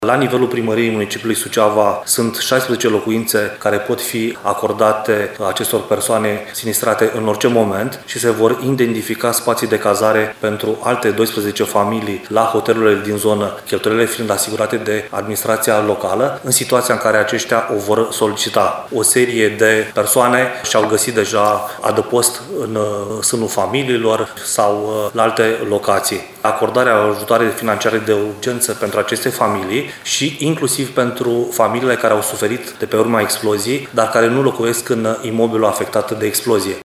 Prefectul ALEXANDRU MOLDOVAN a precizat că persoanele evacuate din locuințe urmează să fie cazate în locuințe ANL puse la dispoziție de Primărie sau în hoteluri.